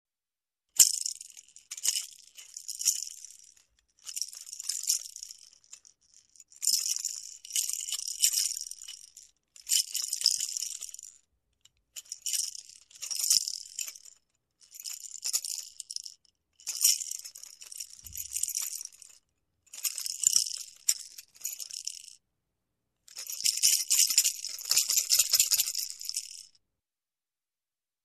Игрушки звуки скачать, слушать онлайн ✔в хорошем качестве